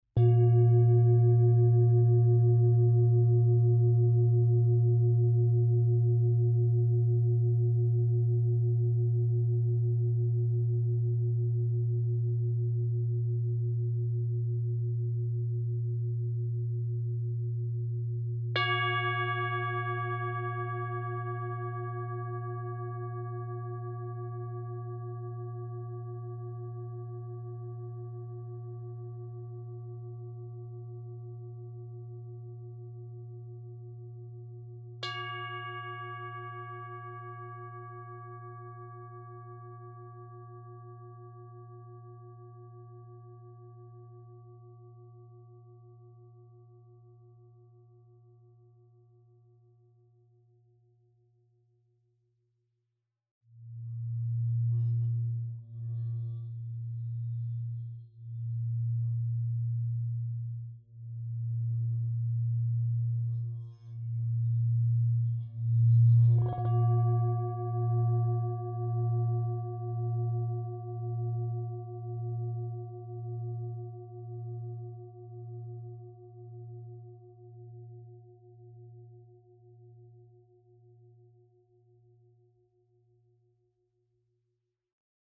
Tibetská misa ø 28 cm/tón A#
Hraním na Tibetskú misu sa jej stena rozvibruje a vydáva nádherné harmonizujúci tóny.
Táto misa je dovezené z Nepálu a jej základný tón je A#
Nahrávka tejto Tibetské misy na počúvanie tu
Materiál kov/mosadz